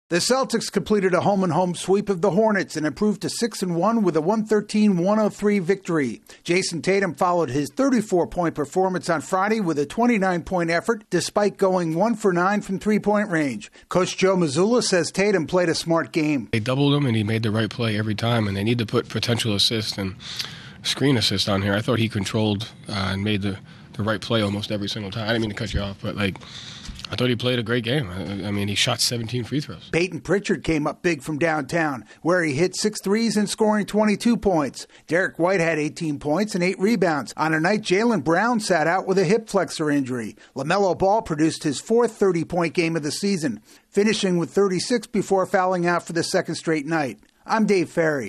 The Celtics beat the Hornets for the second straight night. AP correspondent